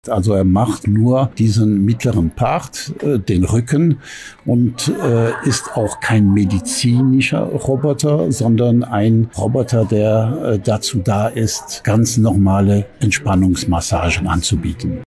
Interview: Der neue Massage-Roboter im Cup Vitalis Hotel Bad Kissingen - PRIMATON